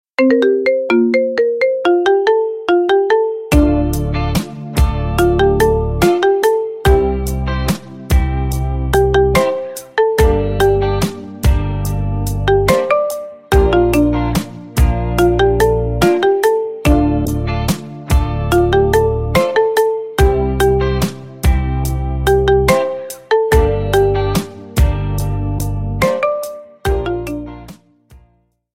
Рингтоны Без Слов
Рингтоны Ремиксы